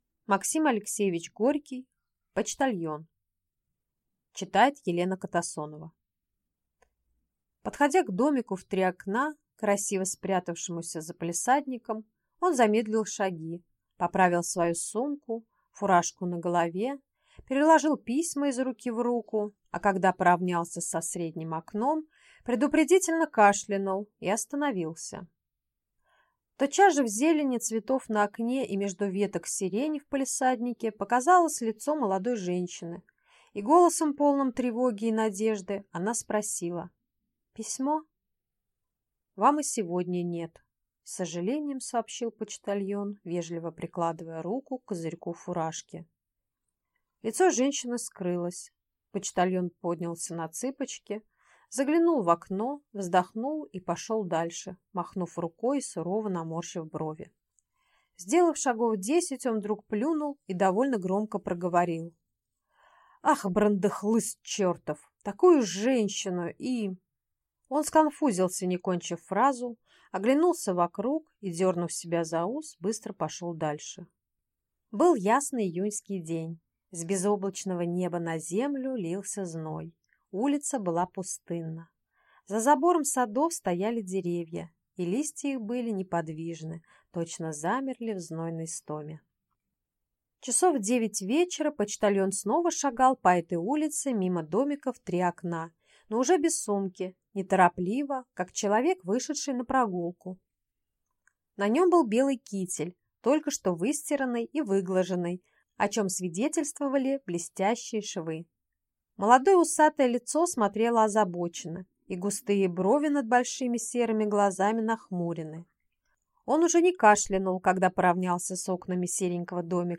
Аудиокнига Почтальон | Библиотека аудиокниг
Автор Максим Горький Читает аудиокнигу